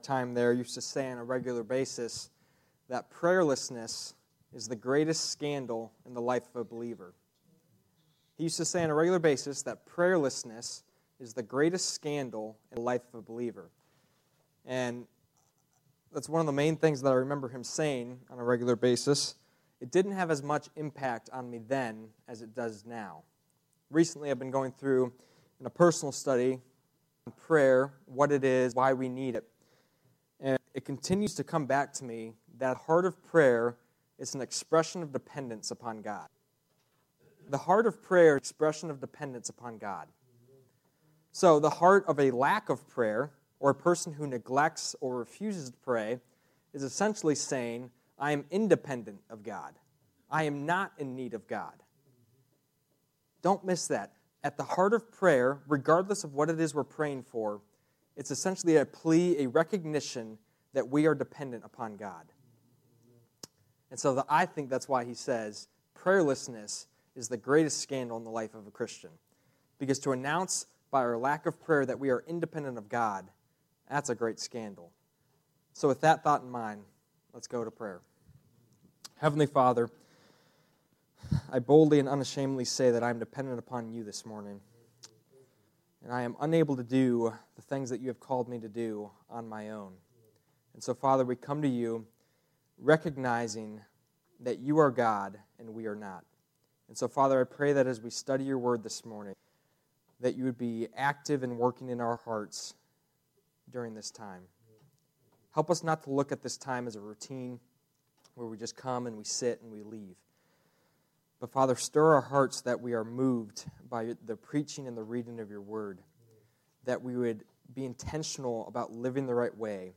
Passage: 2 Peter 1:10-15 Service Type: Sunday Morning